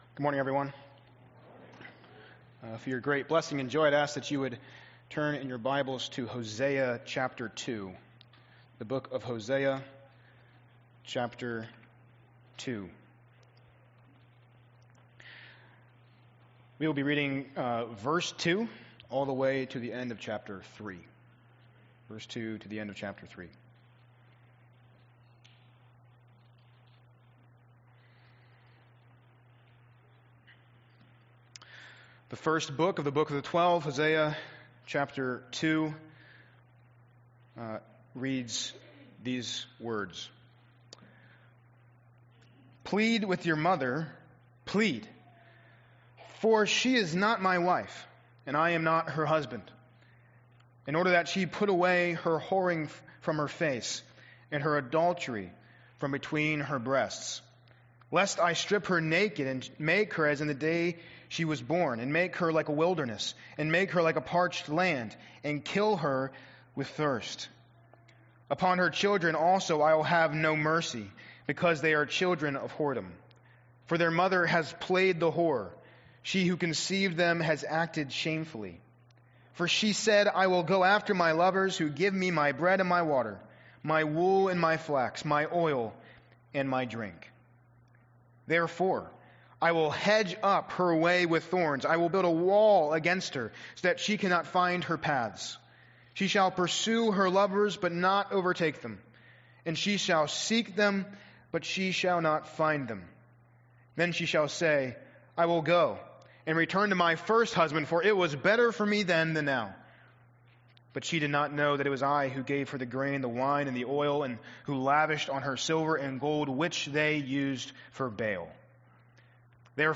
Sermons | Grace Chapel